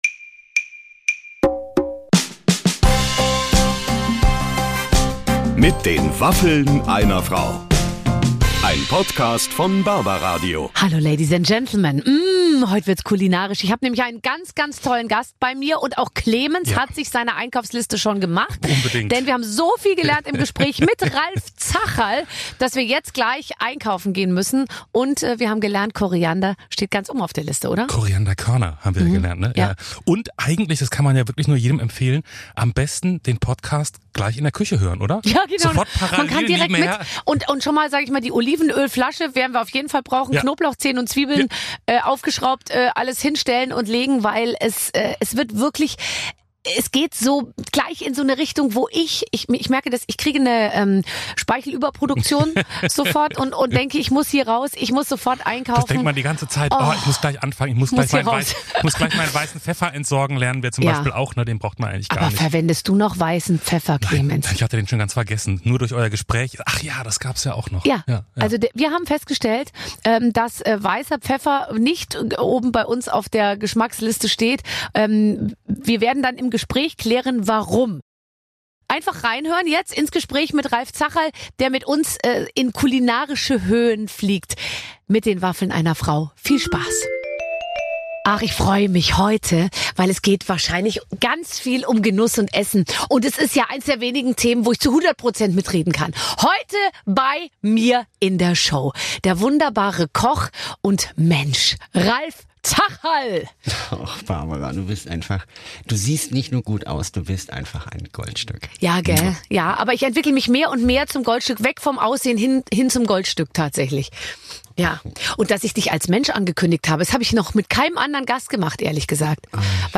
Koch Ralf Zacherl beantwortet eure Fragen: Welche Gewürze sollte man unbedingt zuhause haben? Und wie rette ich ein versalzenes Gericht? Außerdem verrät Ralf Zacherl ein paar seiner liebsten Rezepte und erzählt, warum sein Job oftmals auch sehr stressig ist.